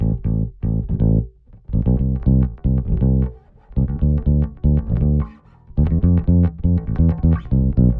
31 Bass PT2.wav